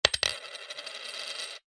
Деньги звуки скачать, слушать онлайн ✔в хорошем качестве
Звук о поступлении денег в web money, падают монетки Скачать звук music_note Деньги , монеты save_as 32.3 Кб schedule 0:04:00 8 2 Теги: mp3 , web money , Деньги , звук , компьютеры , Монеты , оповещение , программы , уведомление